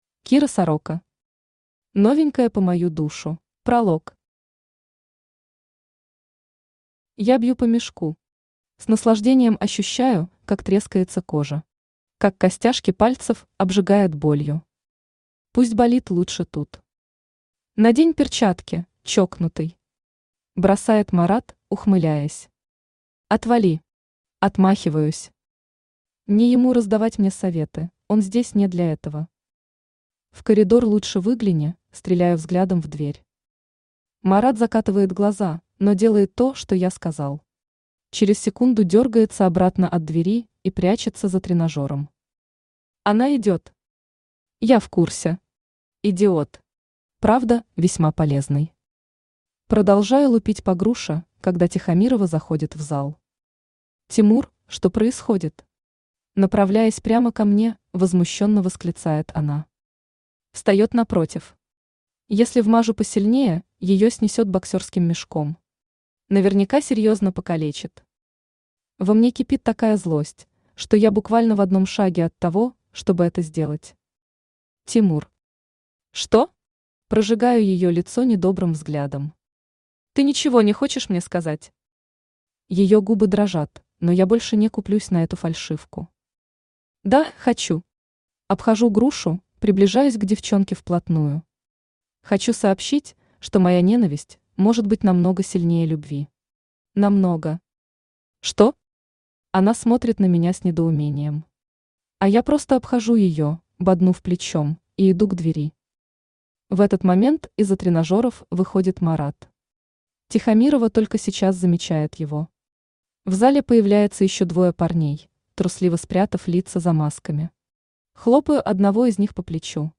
Aудиокнига Новенькая по мою душу Автор Кира Сорока Читает аудиокнигу Авточтец ЛитРес.